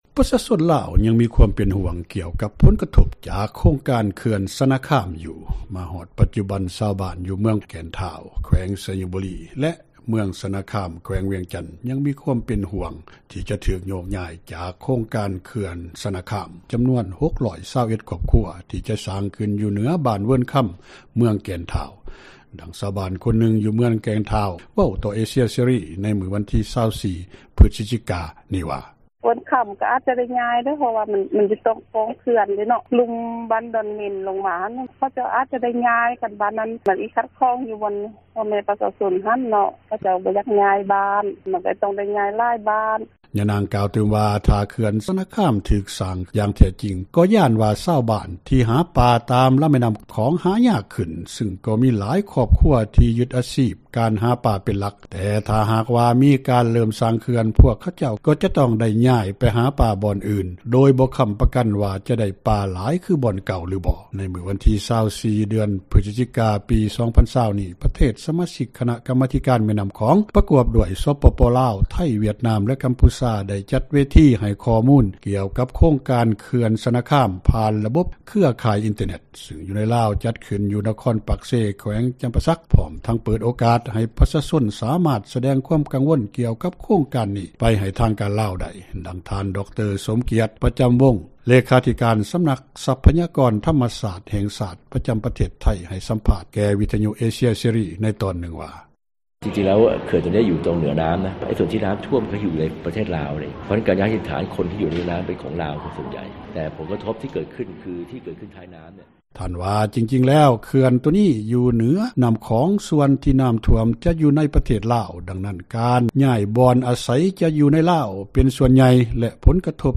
ມາຮອດປັດຈຸບັນ ຊາວບ້ານຢູ່ເມືອງແກ່ນທ້າວ ແຂວງໄຊຍະບູຣີແລະເມືອງຊະນະຄາມ ແຂວງວຽງຈັນ ຈຳນວນ 621 ຄອບຄົວຍັງເປັນຫວ່ງ ທີ່ຈະຖືກໂຍກຍ້າຍຈາກໂຄງການເຂື່ອນຊະນະຄາມ ທີ່ຈະສ້າງຂື້ນຢູ່ເຫນືອບ້ານເວີນຄຳ ເມືອງແກ່ນທ້າວ ດັ່ງຊາວບ້ານຄົນນື່ງຈາກເມືອງແກ່ນທ້່າວ ແຂວງໄຊຍະບູຣີ ກ່າວຕໍ່ເອເຊັຽເສຣີ ໃນມື້ວັນທີ 24 ພືສຈິການີ້້ວ່າ
ນັກວິຊການລາວ ຜູ້ທີ່ເຮັດວຽກກ່ຽວກັບເຣື້ອງການວິຈັຍປາເວົ້າວ່າ ໃນທາງປະຕິບັດແລ້ວ ກອງປະຊຸມດັ່ງກ່າວເປັນພຽງແຕ່ພິທີການເທົ່ານັ້ນ ແລະ ບໍ່ມີຜົນຫຍັງກັບການສ້າງເຂື່ອນ, ດັ່ງທ່ານກ່າວຕໍ່ເອເຊັຽເສີ້ ໃນວັນທີ 24 ພືສຈິກາ ວ່າ: